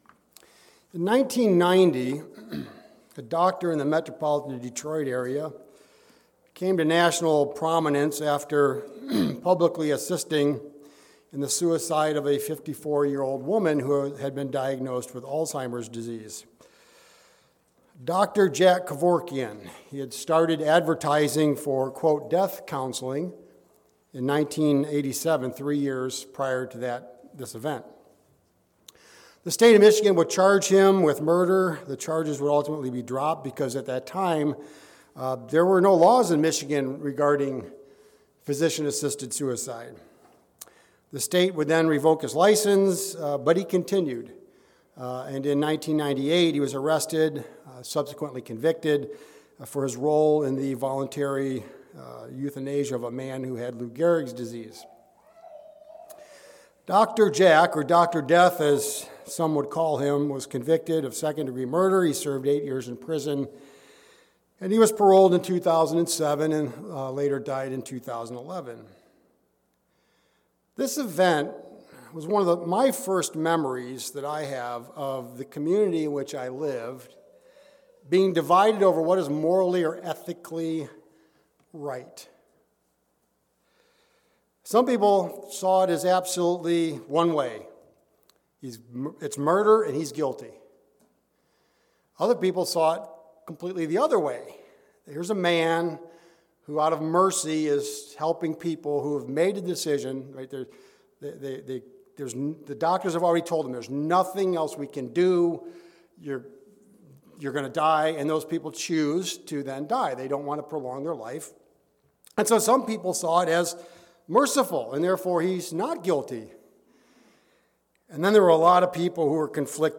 In this sermon, we will explore several gray areas that will help us to think for ourselves, analyzing them with two categories of sin in mind: sins of commission and sins of omission.
Given in Mansfield, OH